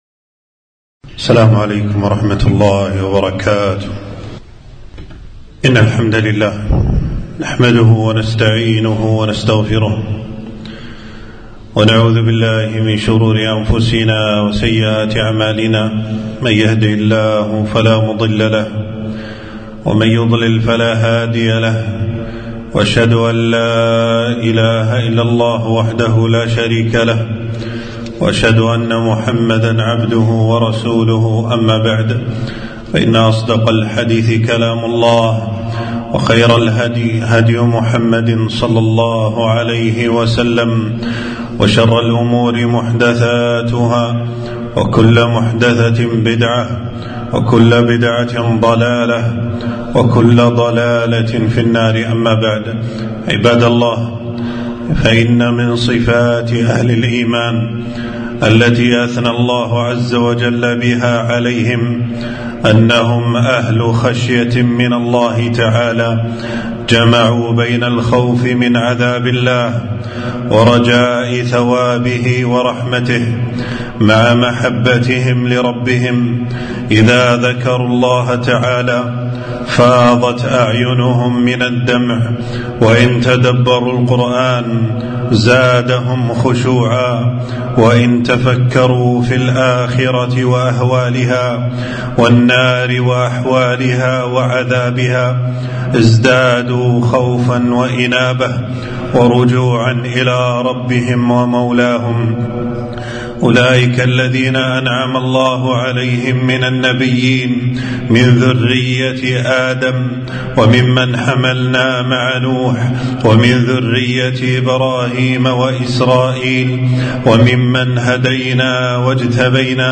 خطبة - البكاء من خشية الله وفضله